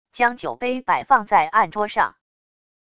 将酒杯摆放在案桌上wav音频